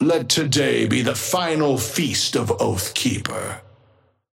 Amber Hand voice line - Let today be the final feast of Oathkeeper.
Patron_male_ally_ghost_oathkeeper_5i_start_02.mp3